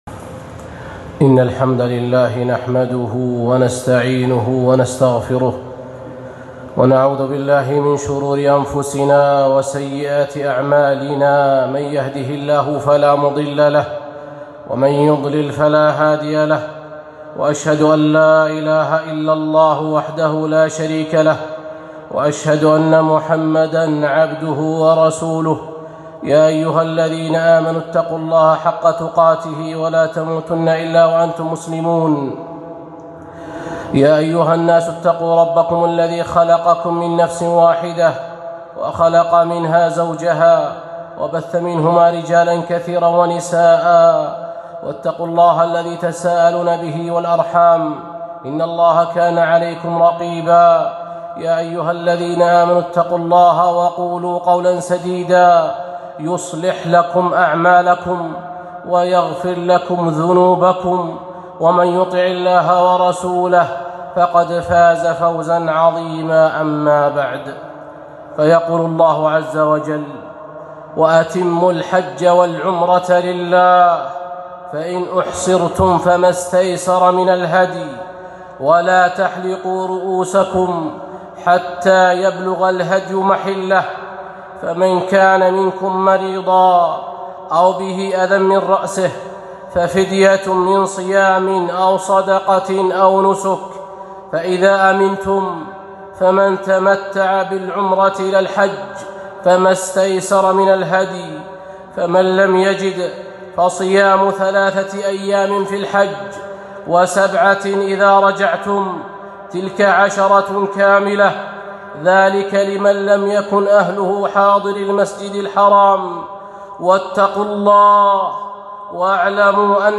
خطبة - توجيهات من آيات الحج